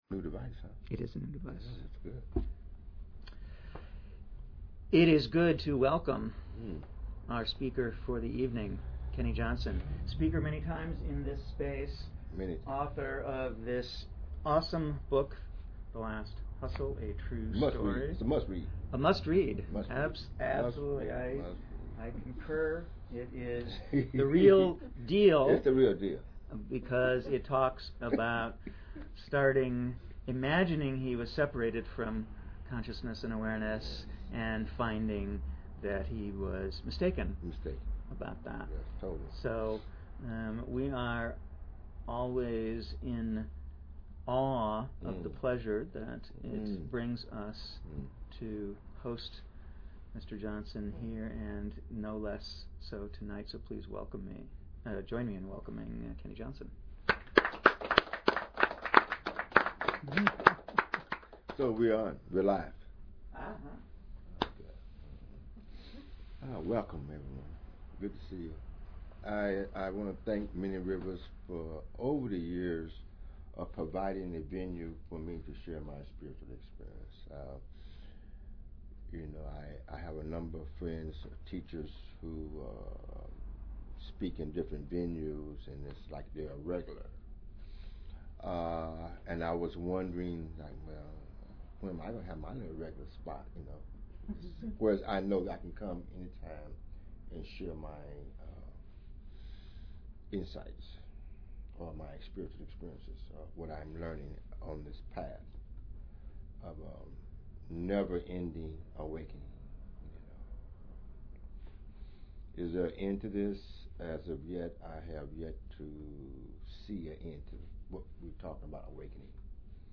Archive of an event at Sonoma County's largest spiritual bookstore and premium loose leaf tea shop.
Satsang